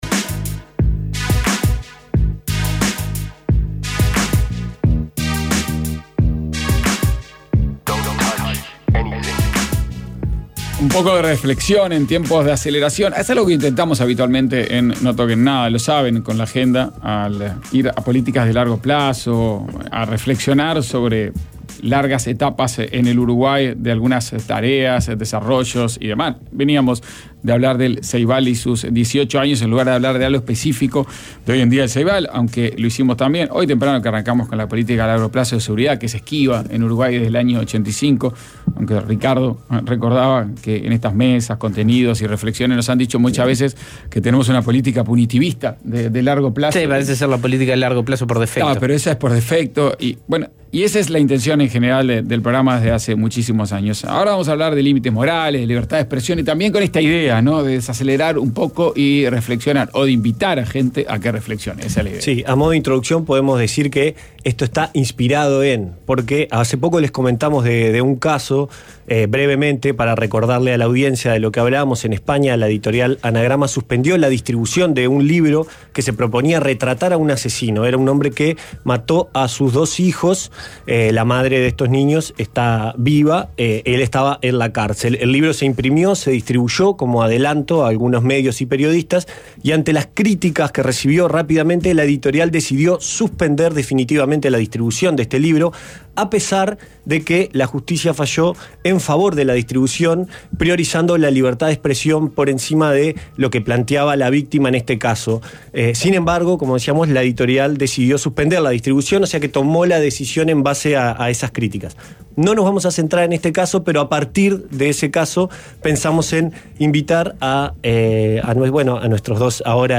Filtraciones, un debate sobre responsabilidades y penas